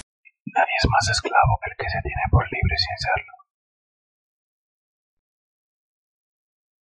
Descarga de Sonidos mp3 Gratis: susurro 1.
oscuro_2.mp3